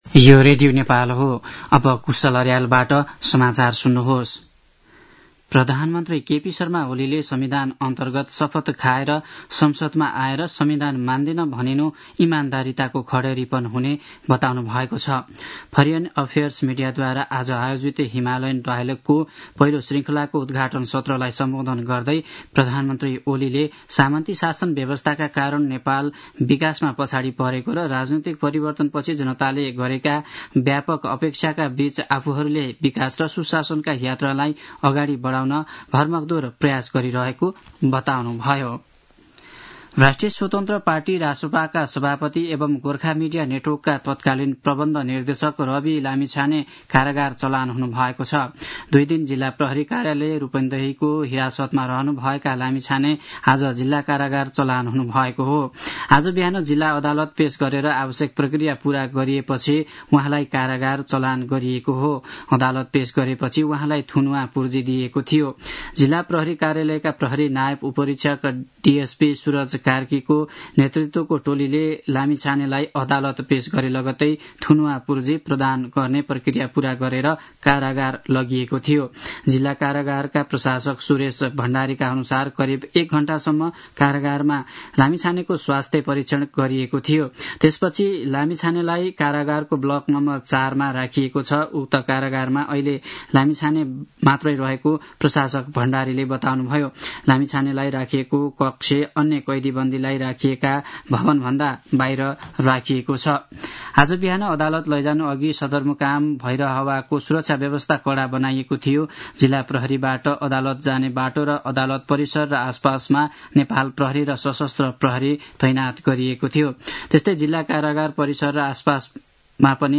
दिउँसो ४ बजेको नेपाली समाचार : २५ चैत , २०८१
4-pm-news-1-1.mp3